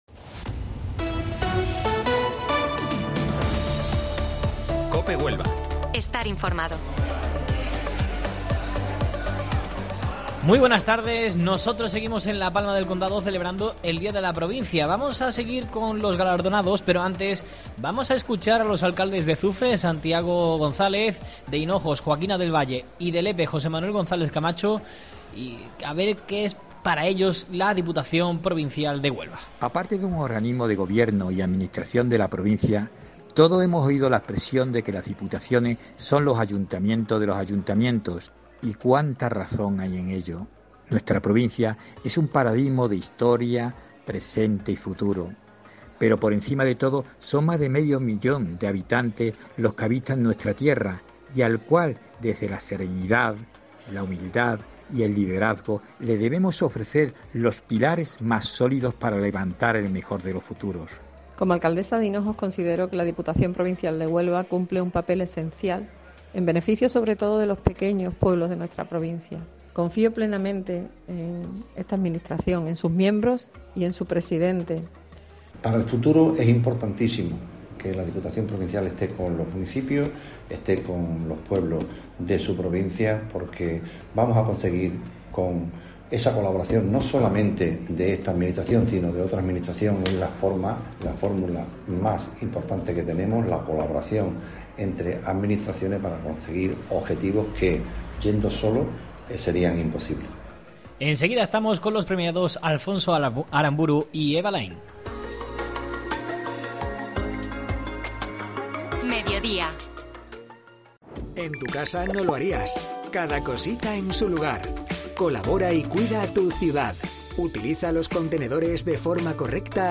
AUDIO: Desde La Palma, con motivo del Día de la Provincia, entrevistamos a los galardonados con la Medalla de Oro de Huelva.